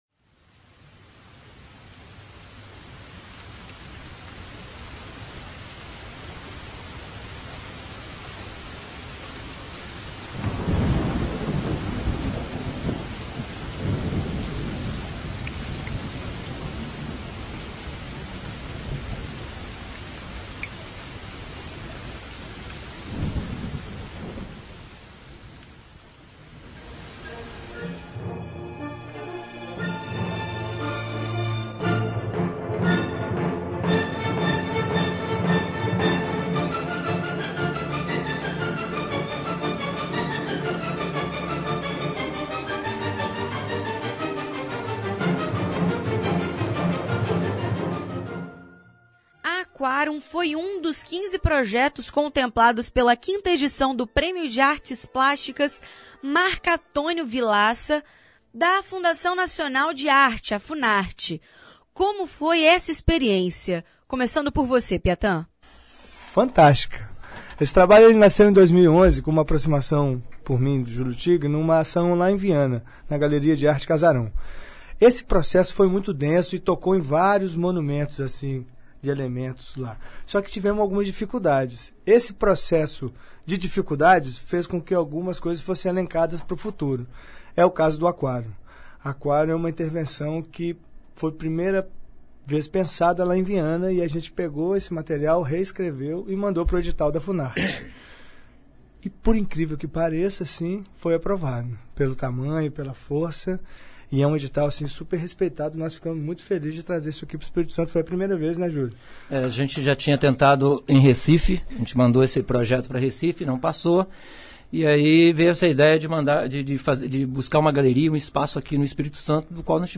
Confira a entrevista com os artista.